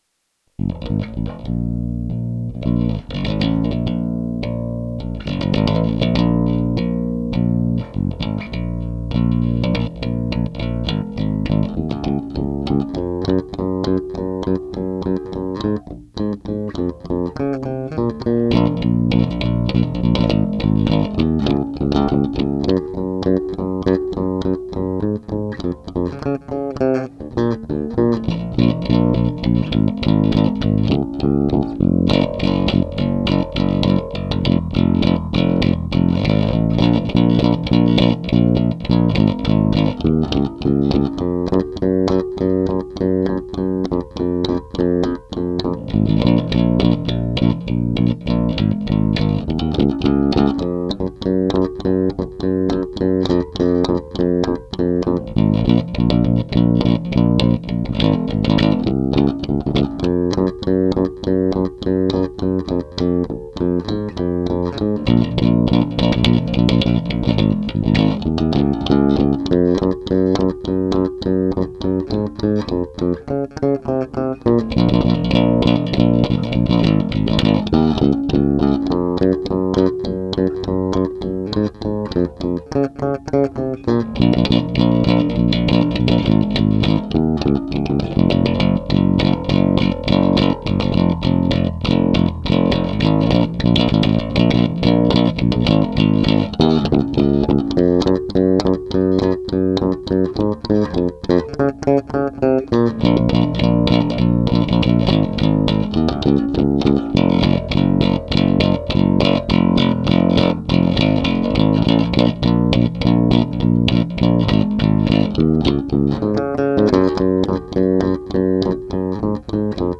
(bass only)